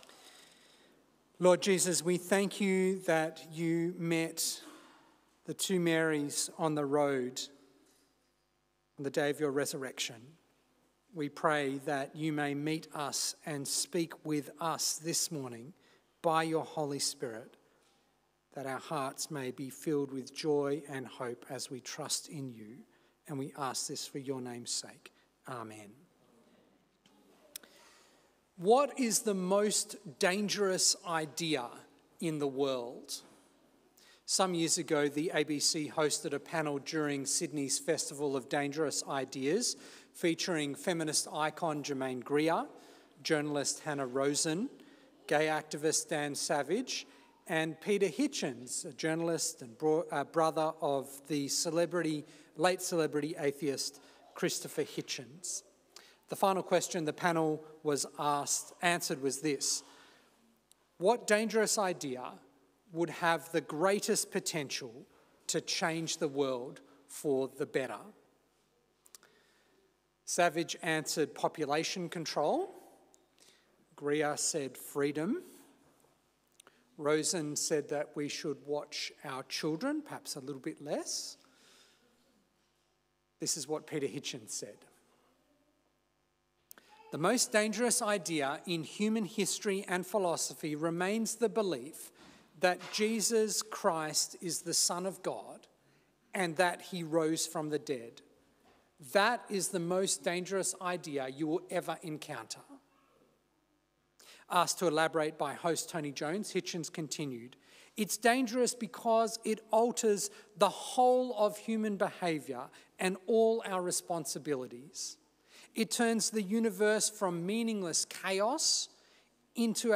An Easter Sunday sermon
St-Georges-Battery-Point-Easter-Sunday-Service-2026.m4a